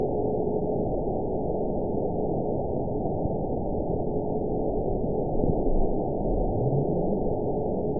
event 922224 date 12/28/24 time 12:26:39 GMT (4 months ago) score 9.71 location TSS-AB04 detected by nrw target species NRW annotations +NRW Spectrogram: Frequency (kHz) vs. Time (s) audio not available .wav